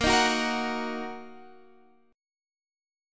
A#sus4#5 Chord